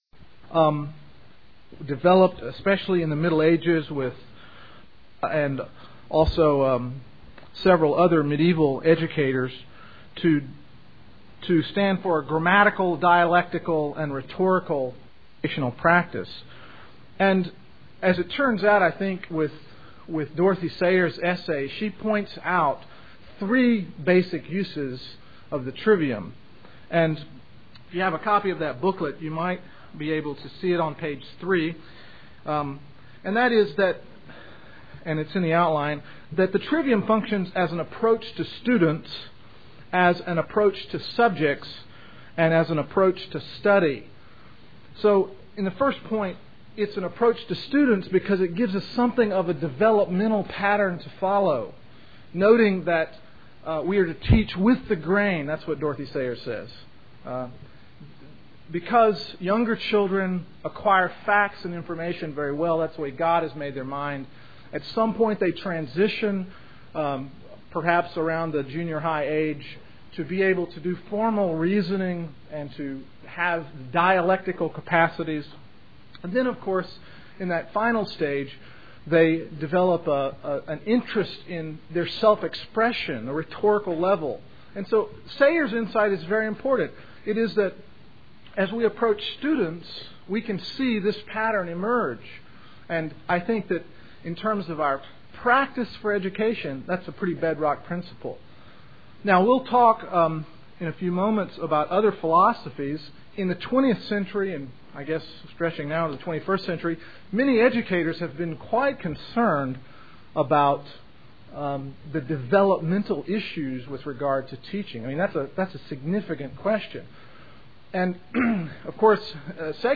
2002 Workshop Talk | 0:42:50 | All Grade Levels, Culture & Faith